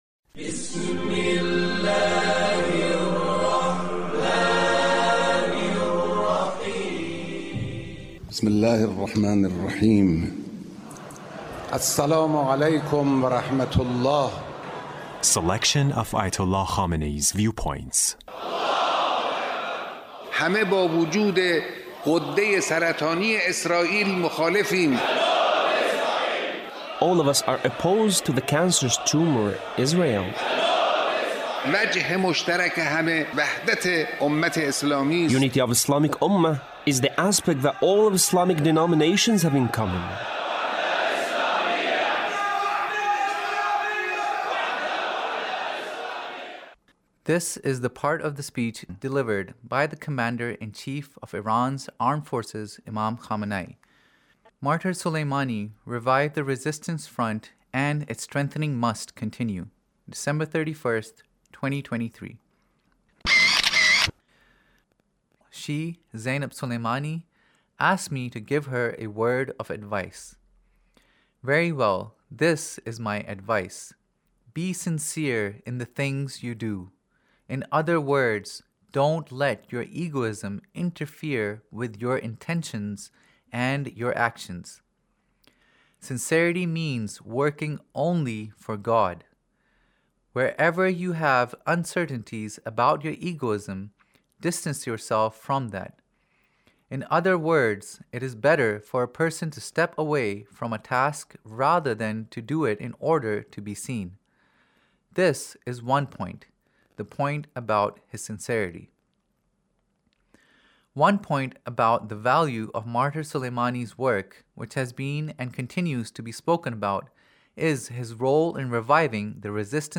Leader's Speech in a meeting with Martyr Soleimani's Family